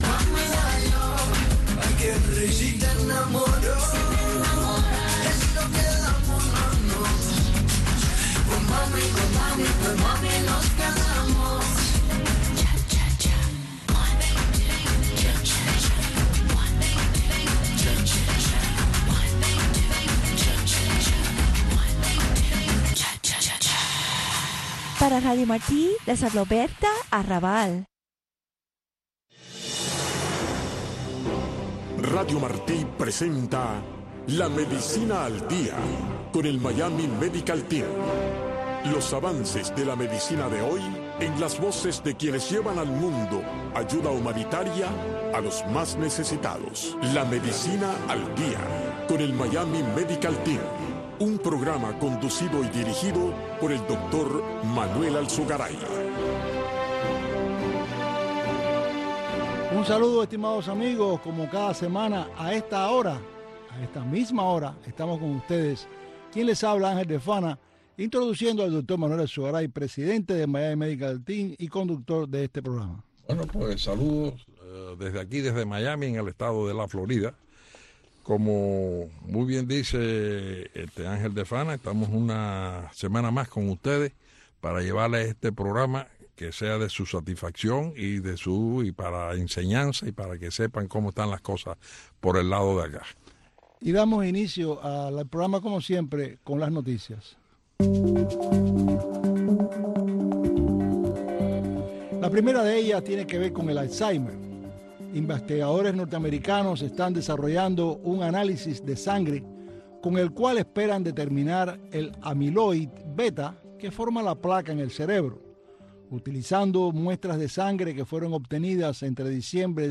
Un programa concebido para que conozcas los avances de la medicina en el mundo de hoy, en la voz de consagrados galenos y diversos profesionales del mundo de la medicina.